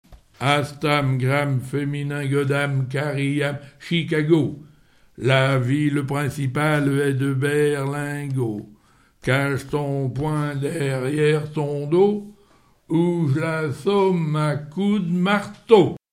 enfantine : lettrée d'école
Pièce musicale inédite